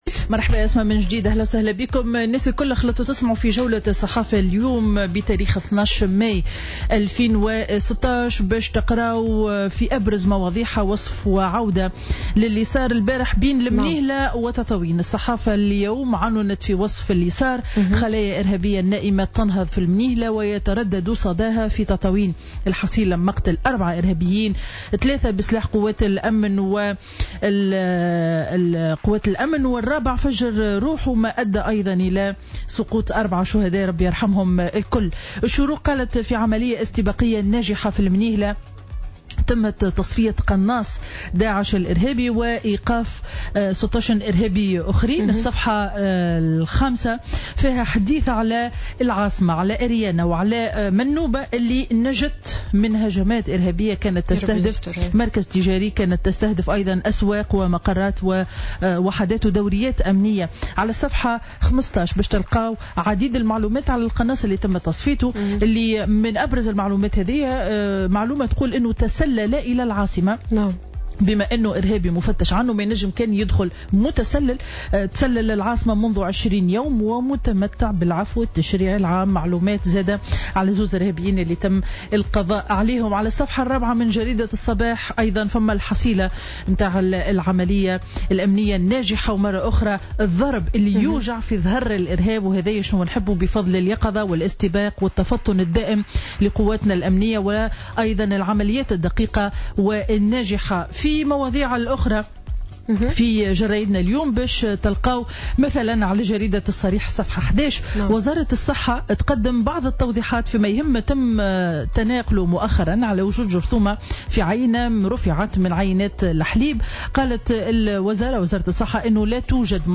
Revue de presse du jeudi 12 mai 2016